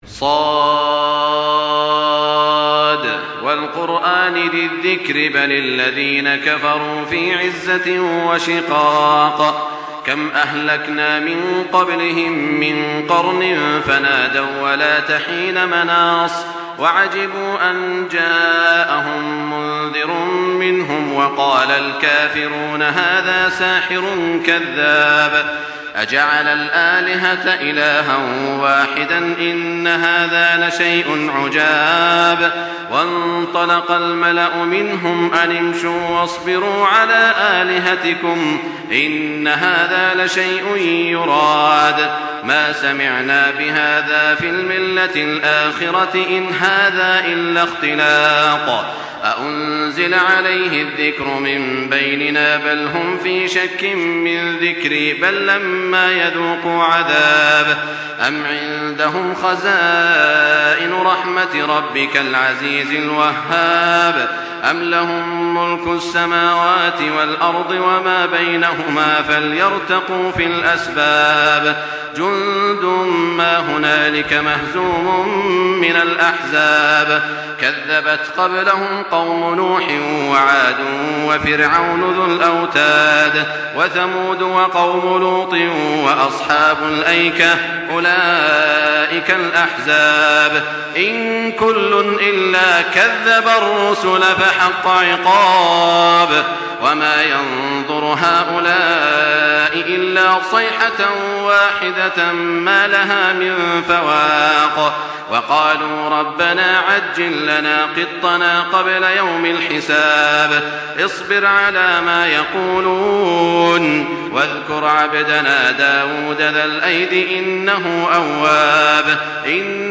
Surah Sad MP3 in the Voice of Makkah Taraweeh 1424 in Hafs Narration
Listen and download the full recitation in MP3 format via direct and fast links in multiple qualities to your mobile phone.